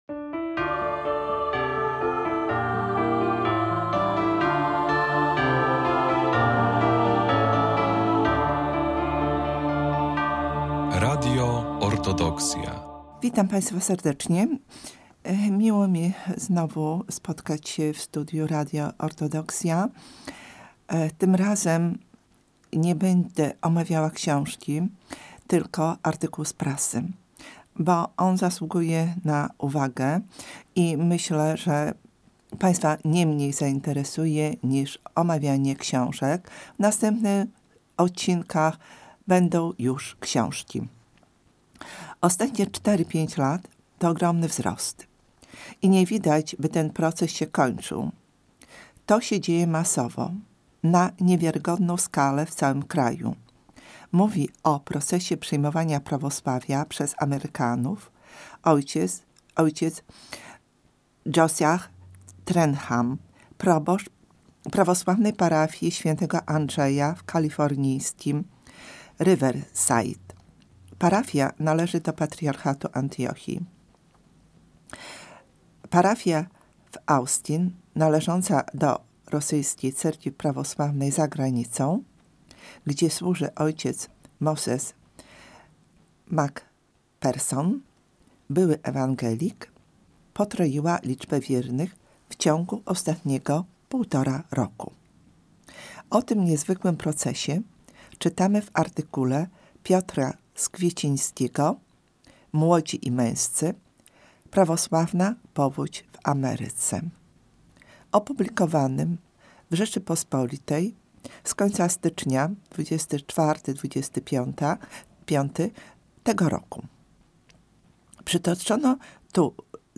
audycja o książkach